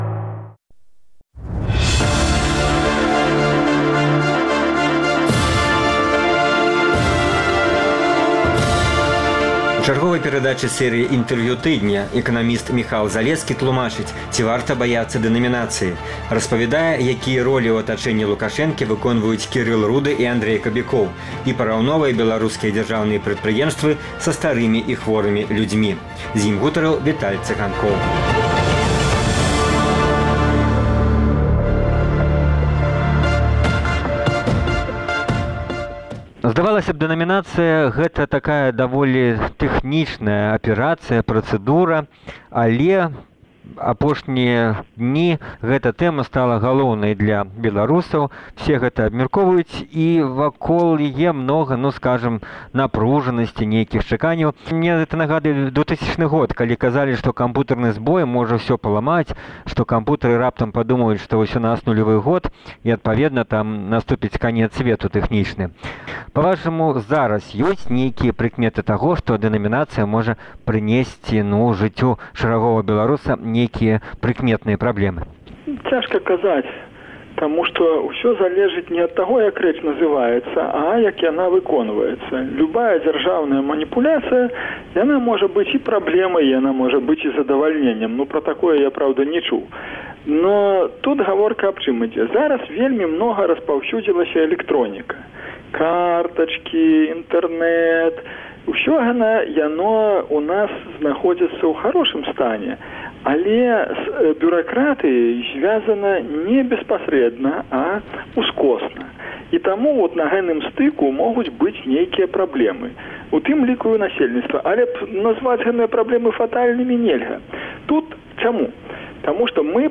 Інтэрвію тыдня